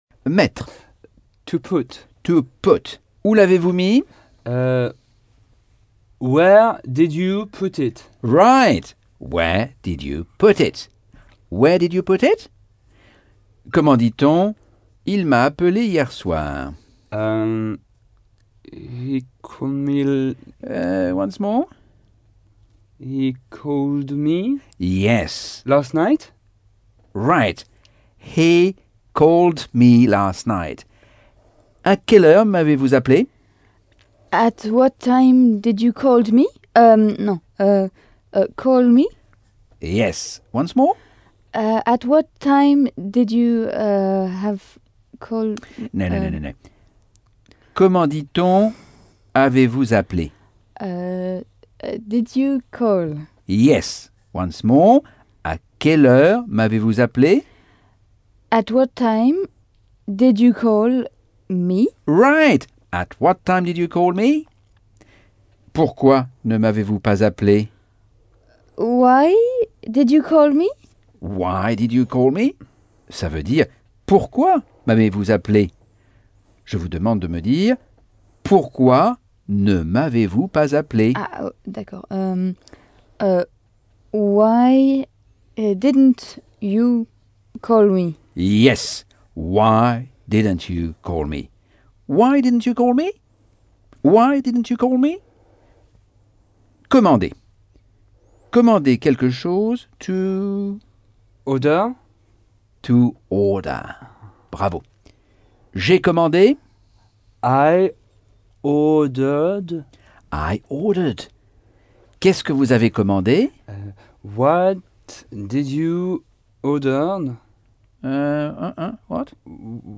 Leçon 11 - Cours audio Anglais par Michel Thomas - Chapitre 5